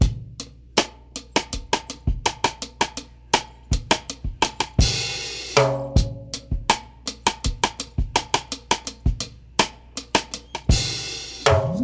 • 108 Bpm Drum Loop A Key.wav
Free drum groove - kick tuned to the A note. Loudest frequency: 2977Hz
108-bpm-drum-loop-a-key-j5W.wav